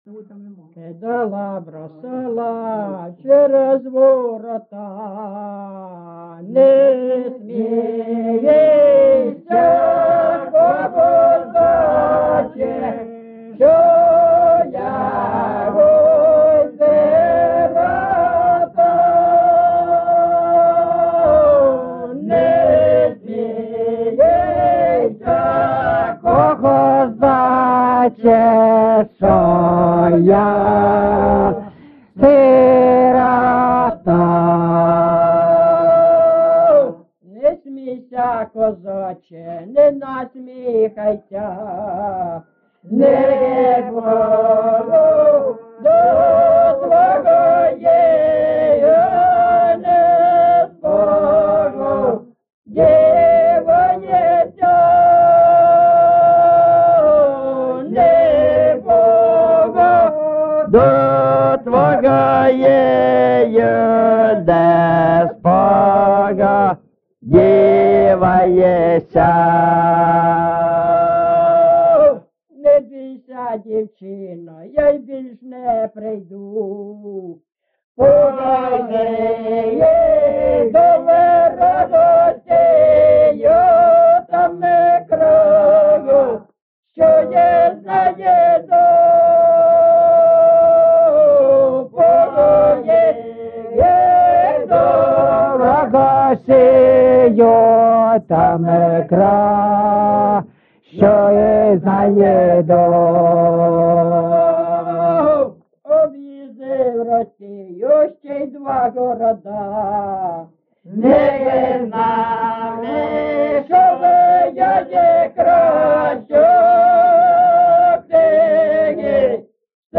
GenrePersonal and Family Life
Recording locationLyman, Zmiivskyi (Chuhuivskyi) District, Kharkiv obl., Ukraine, Sloboda Ukraine